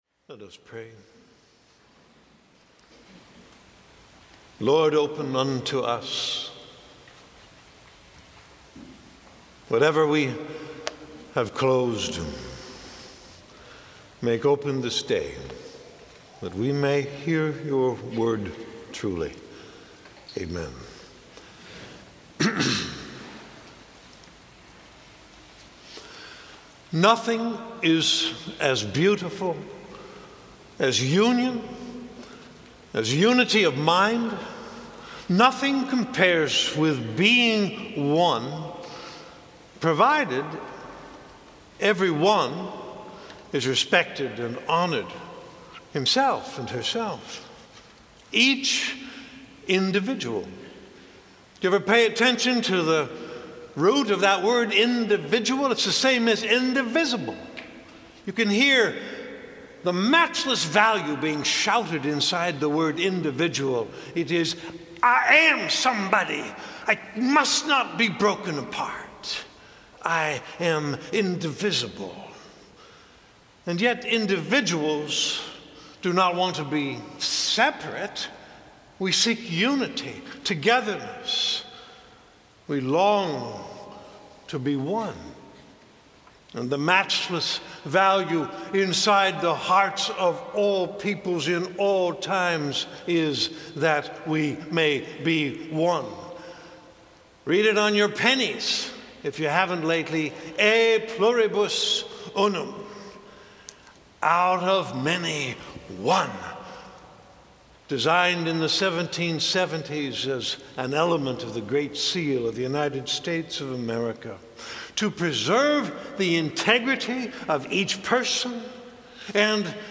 sermon 2013